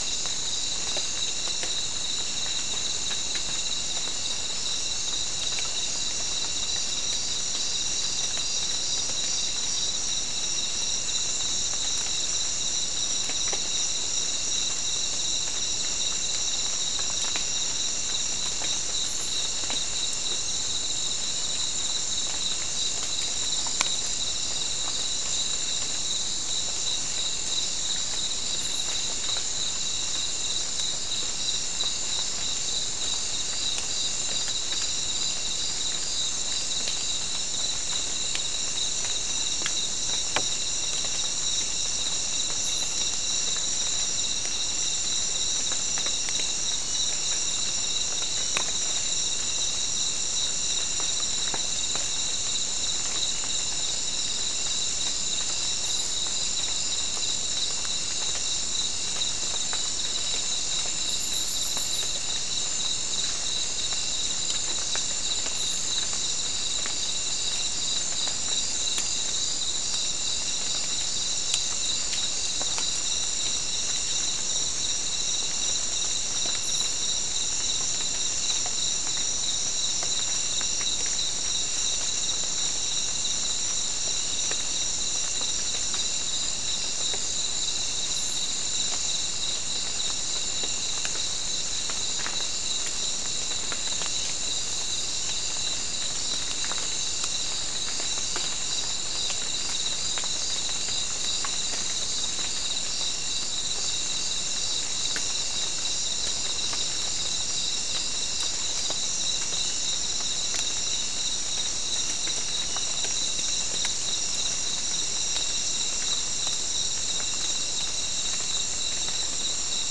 Soundscape
Recorder: SM3